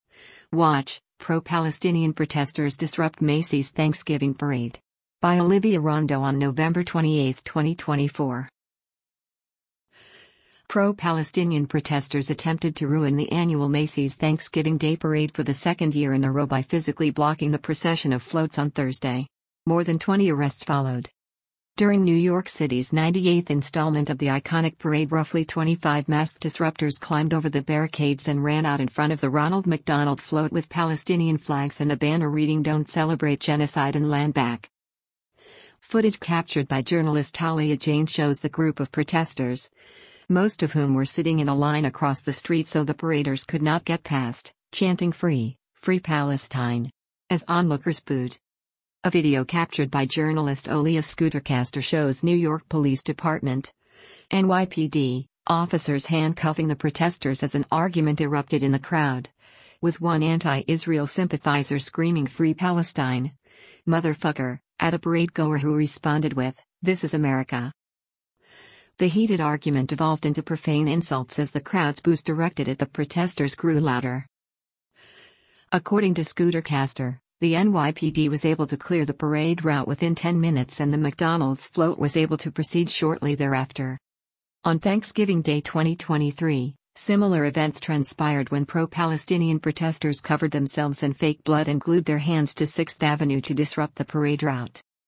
The heated argument devolved into profane insults as the crowd’s “boos” directed at the protesters grew louder.